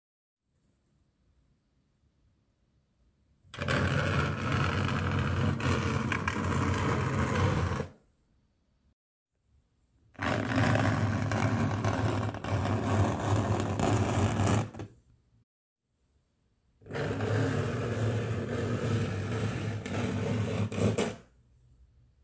Download Scratch sound effect for free.
Scratch